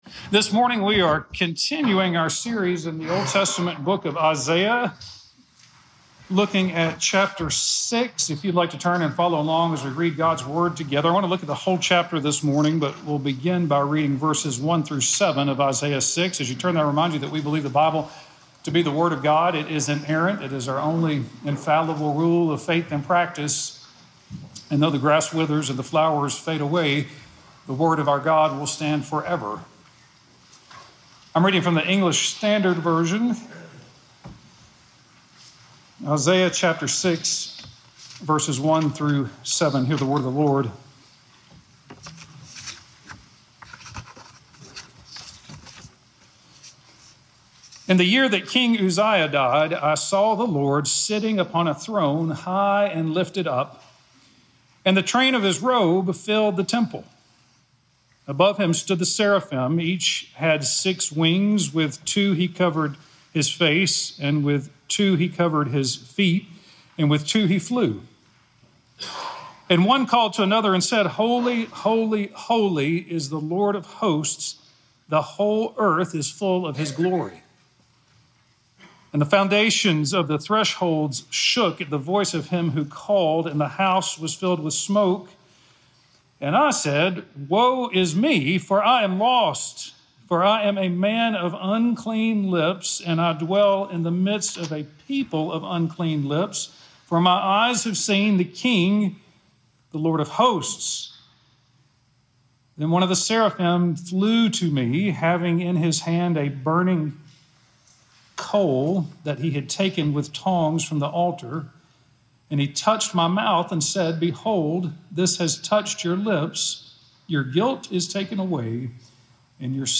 Meeting God Sermon